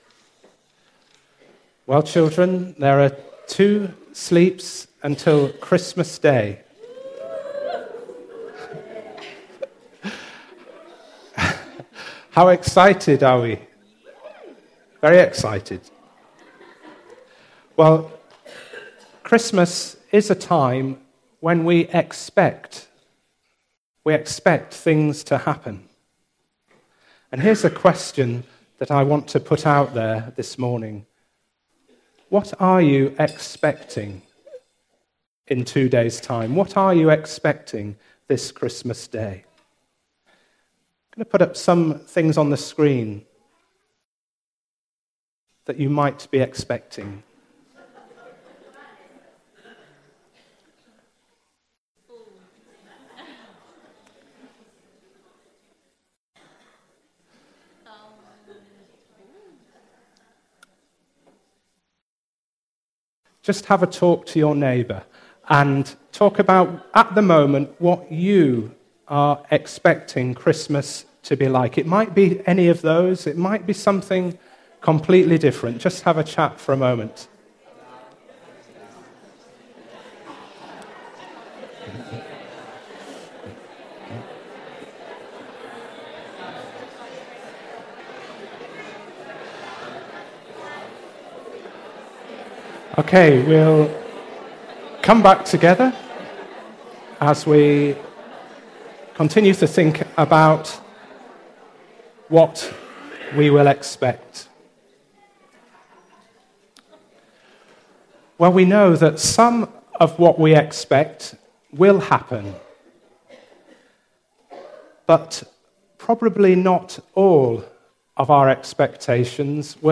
An all age service of worship in a contemporary style, incorporating the making of Christingles.
Service Type: All Age Worship